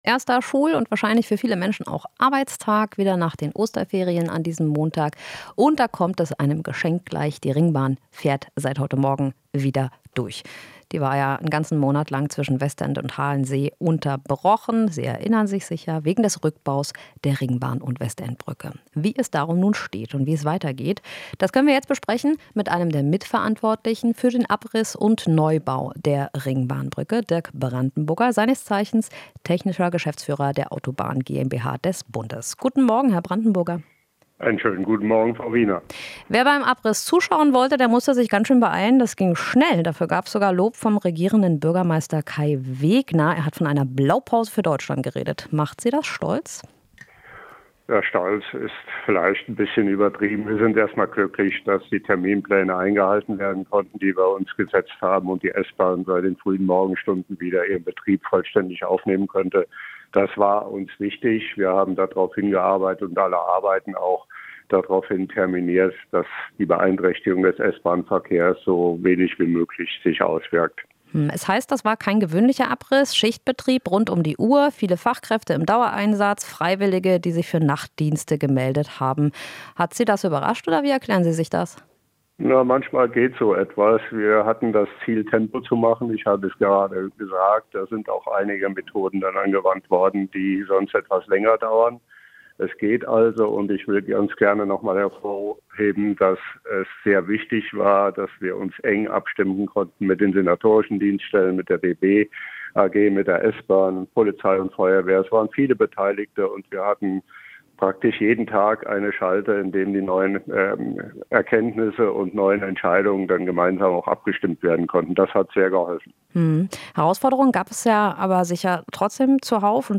Interview - Nach Brückenabriss: Ringbahn fährt wieder wie gewohnt